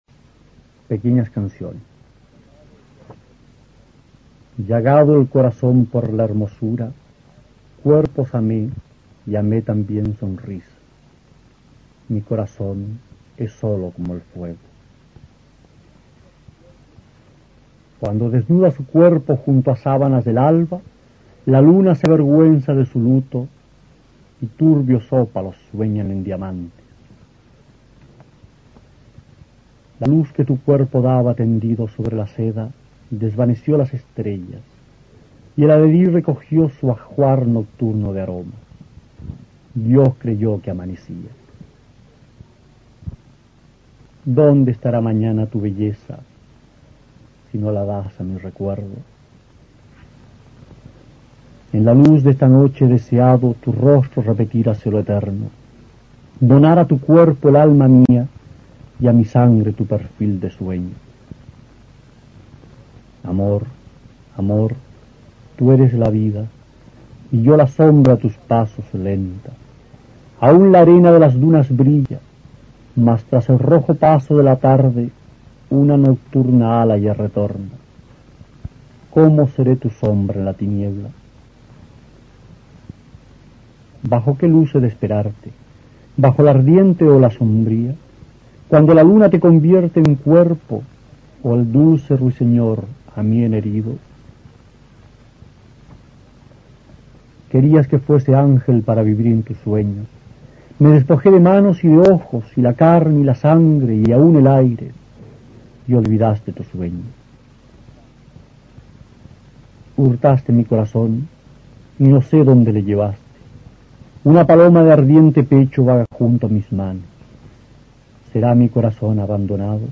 Aquí se puede escuchar al poeta chileno Roque Esteban Scarpa (1914-1995) recitando algunos versos de su libro "Cancionero de Hammud" (1942).